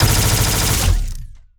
GUNAuto_Plasmid Machinegun Burst_03_SFRMS_SCIWPNS.wav